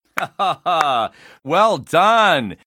hahaa-clap-well-done